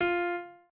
piano3_8.ogg